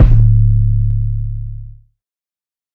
Machine808_JJ.wav